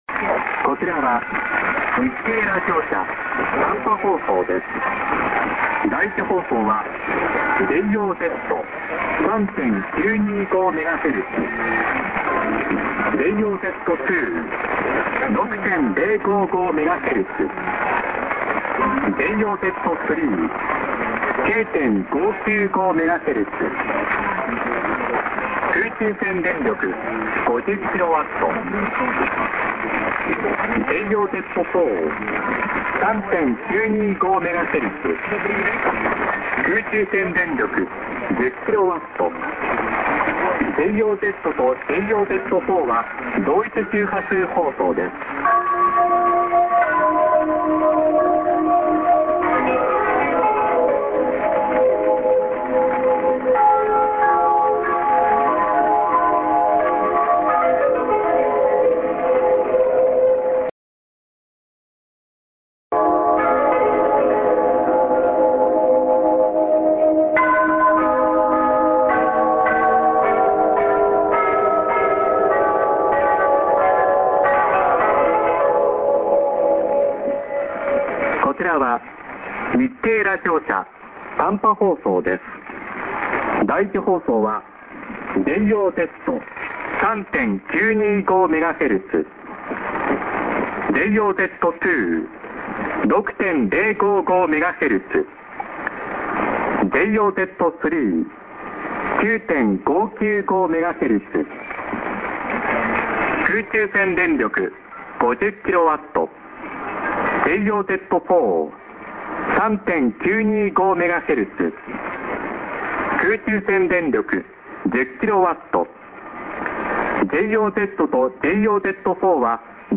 s/on 25':ANN(man:ID+SKJ)->music->:chime/cut/music->29'20":ANN(man:ID+SKJ)->prog